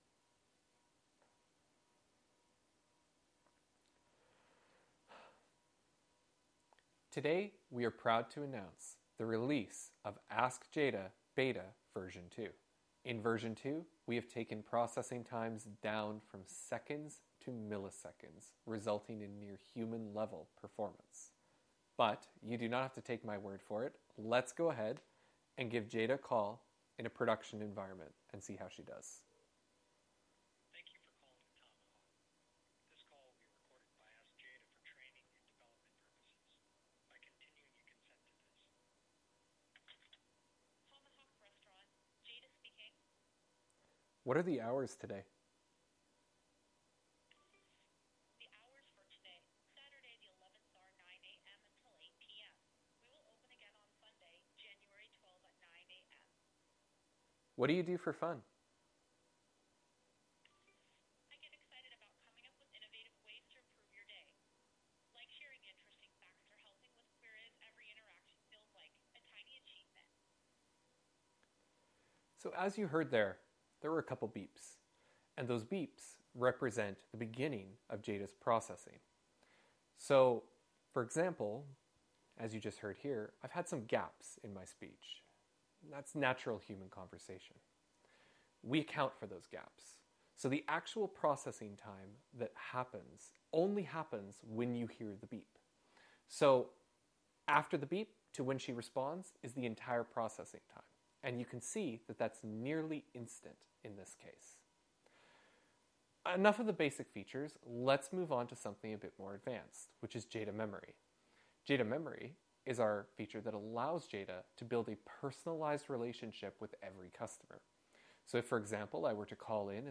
The raw lav mic track is available here:
raw_audio_v2_live_demo.mp3